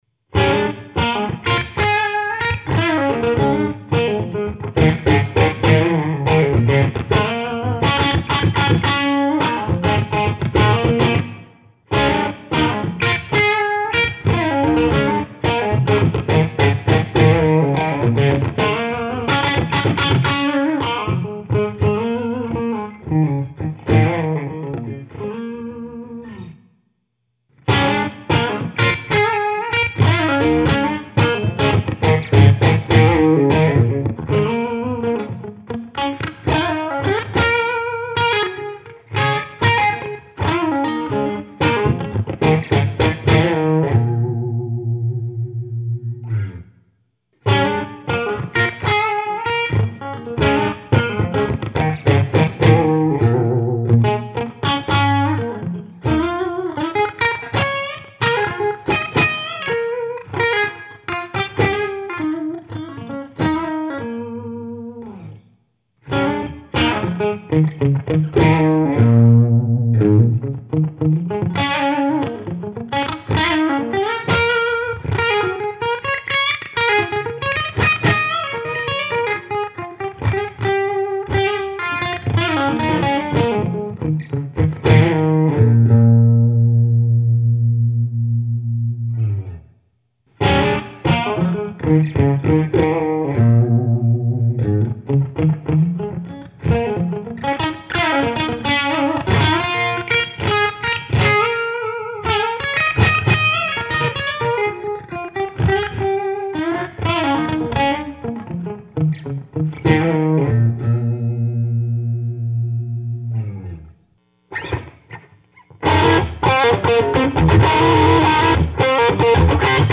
Section 1: Clean Tone, Attenuator in the bypass mode
Section 2: Clean Tone, 3dB attenuation (step mode "A")
Section 6: Clean Tone, low bedroom mode (variable mode)
Section 7: Overdriven Tone, Attenuator in the bypass mode
Section 12: Overdriven Tone, low bedroom mode (variable mode)
Guitar: "b3" Model - Bombardier with Lollar Imperials pickups
Amplifier: Elmwood Modena 90, a 90 watt amplifier (no amplifier EQ or control adjustments during the recording)
Aracom_PRX150_Pro_no_voice.mp3